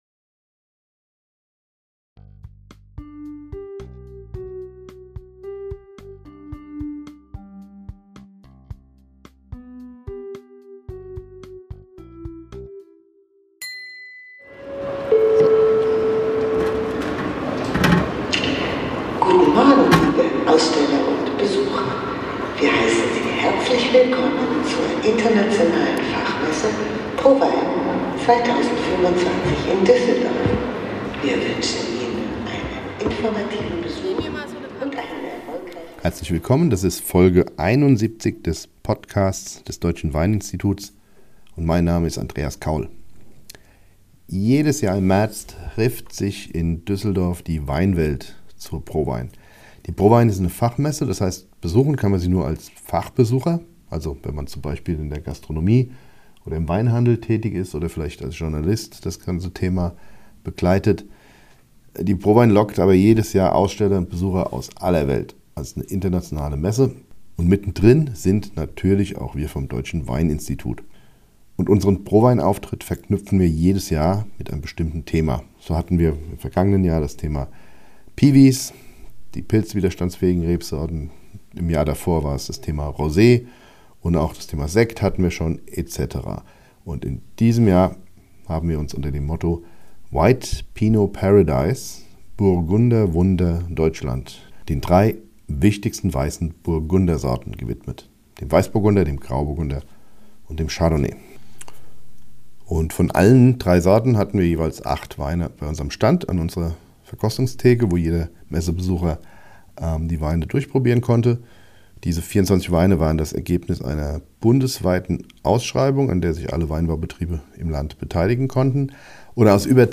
Beschreibung vor 1 Jahr Weißburgunder, Grauburgunder und Chardonnay sind weltweit enorm beliebt. Und weiße Burgunder aus Deutschland sind ausgezeichnet! Auf der Fachmesse ProWein 2025 sprach